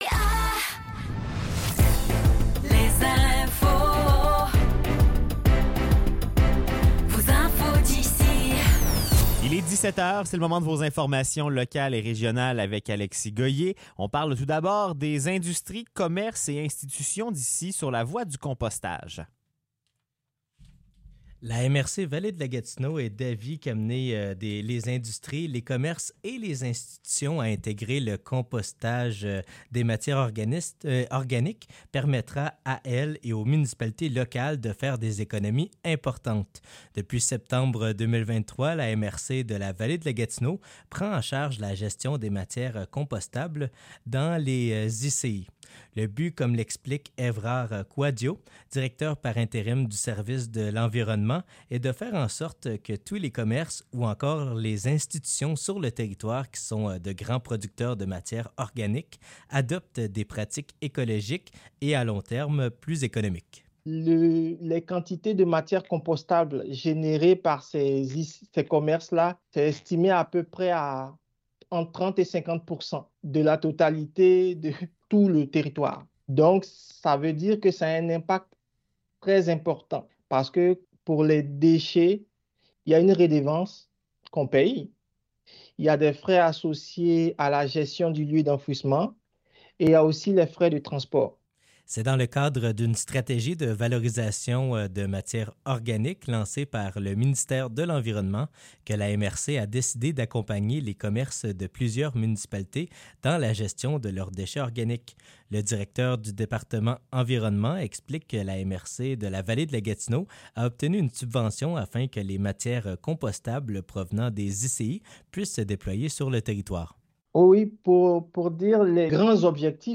Nouvelles locales - 27 février 2024 - 17 h